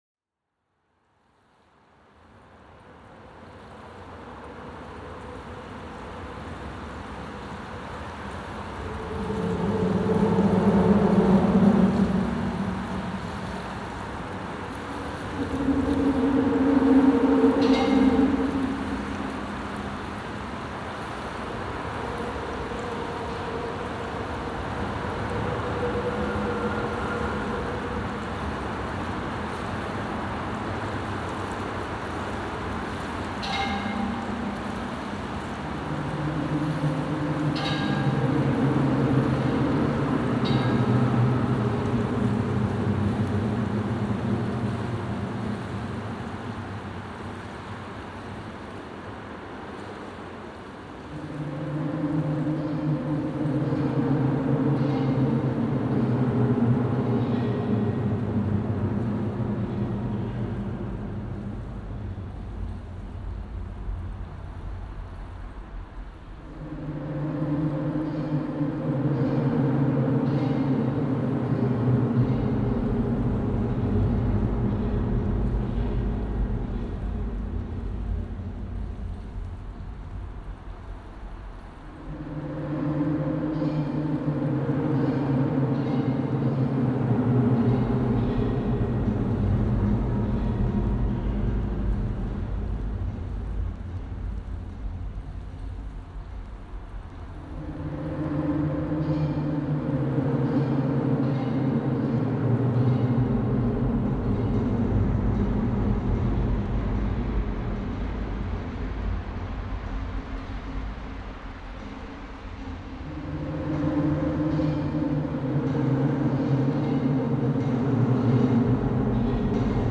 Minneapolis interstate bridge reimagined